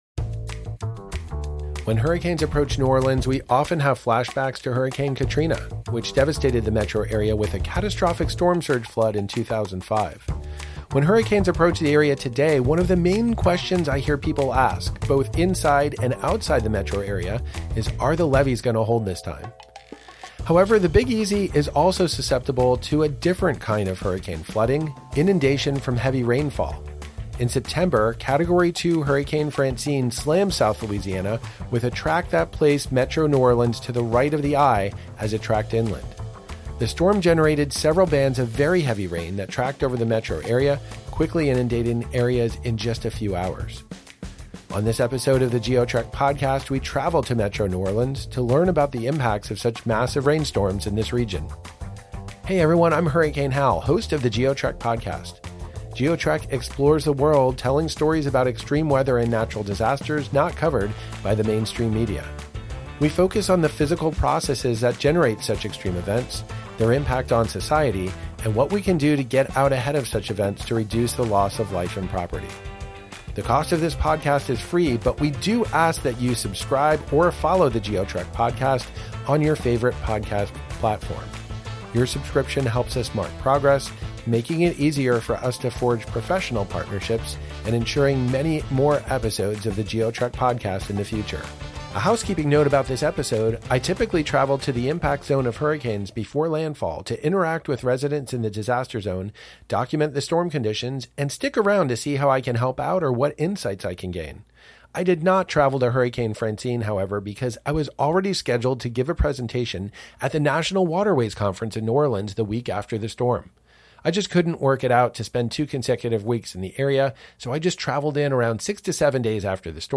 This podcast explores landscapes and paints a contrast between tropical South Florida and the open spaces of Europe. Listeners on this episode are invited to come along on an audio slough slog, which is a water hike through the Everglades.